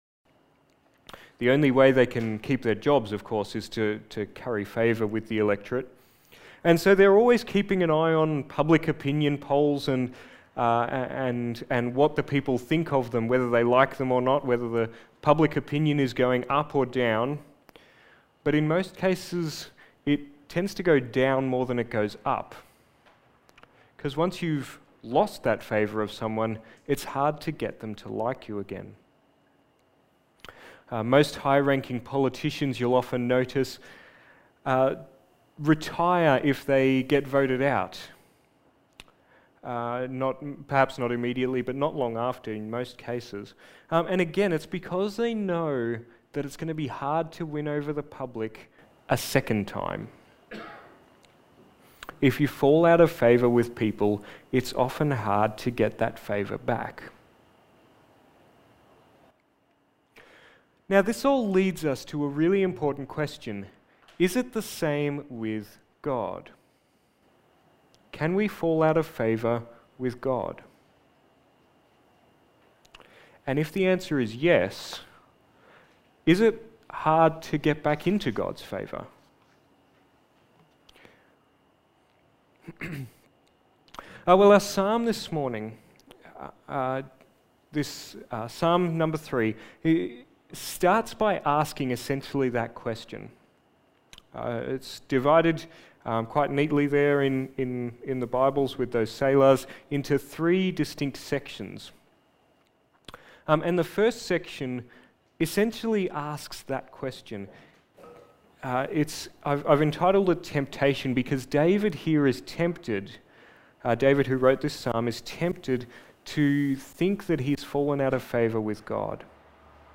Topical Sermon Passage: Psalm 3:1-8 Service Type: Sunday Morning